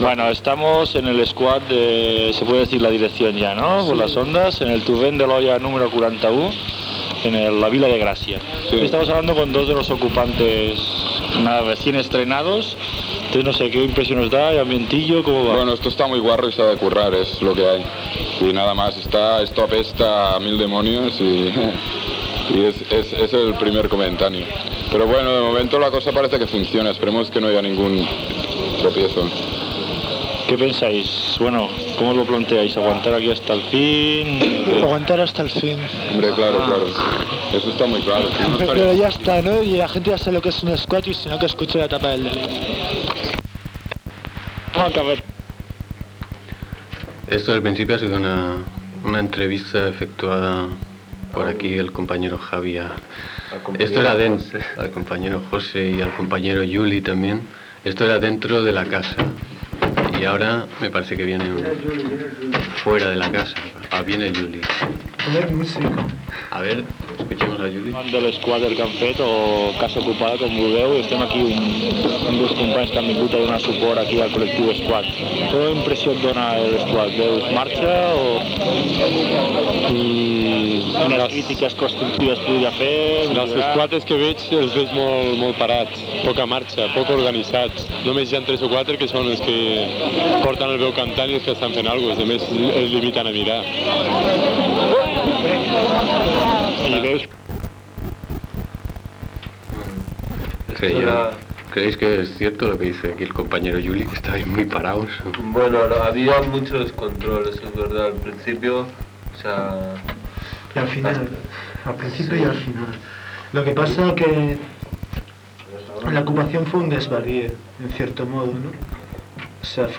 Entrevista a membres del moviment squatter sobre l'ocupació feta al carrer Torrent de l'Olla, 41 de Gràcia i que van ser desallotjats per la policia nacional el 7 de desembre de 1984.
Informatiu
FM